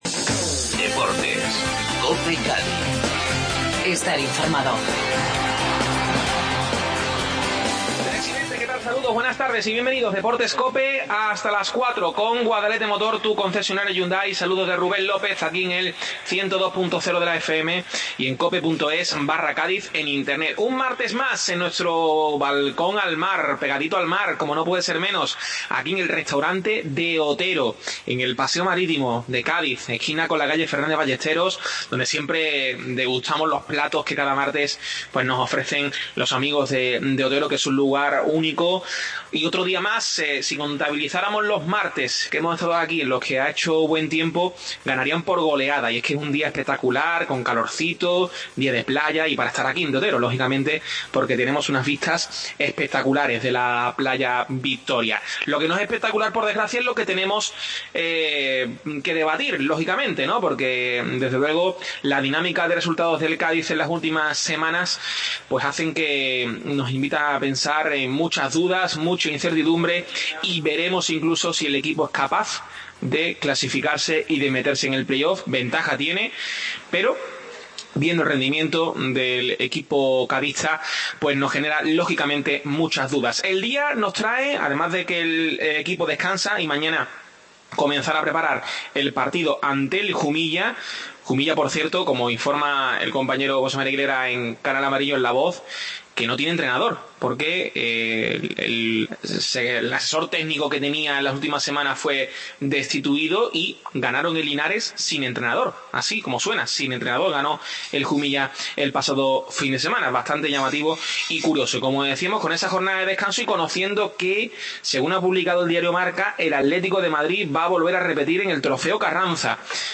Desde el Restaurante De Otero tertulia